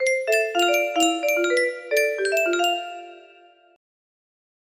demo music box melody